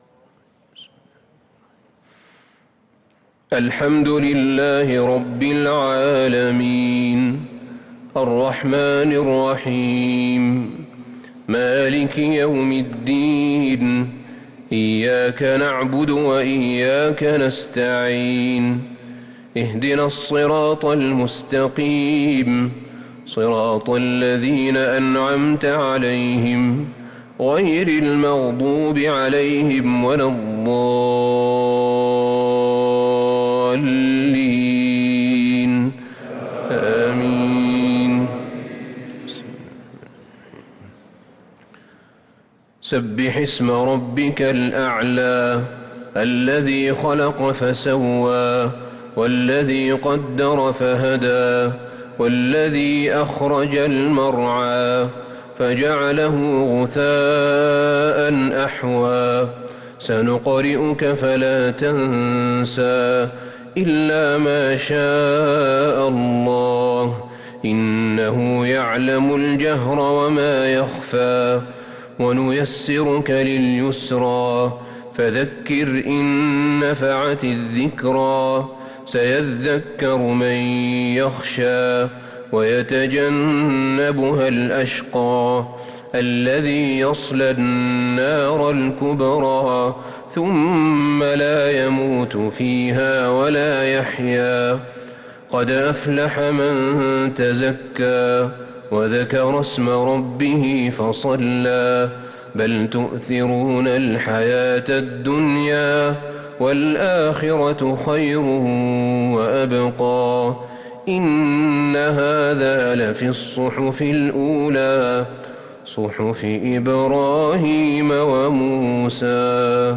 صلاة الجمعة ٧ محرم ١٤٤٤هـ سورتي الأعلى و الغاشية |Jumu’ah prayer from Surah Al-a’ala & Al-Ghashiya 5-8-2022 > 1444 🕌 > الفروض - تلاوات الحرمين